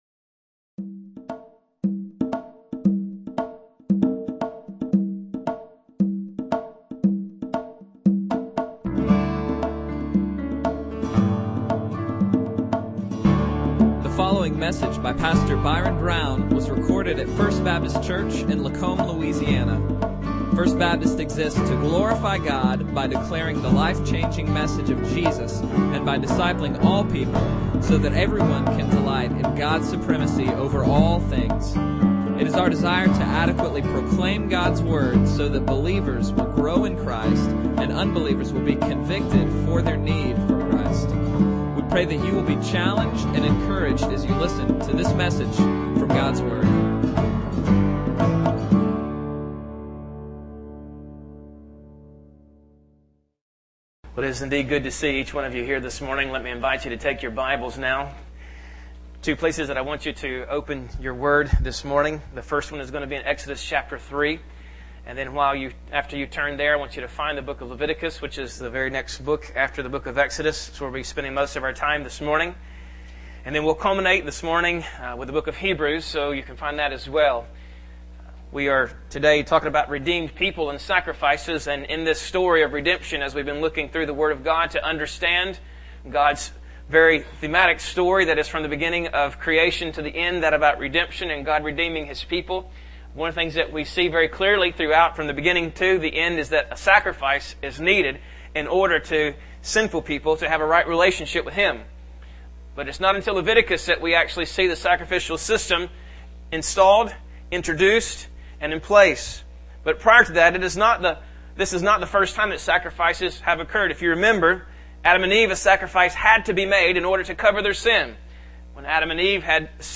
Bible Text: Leviticus | Preacher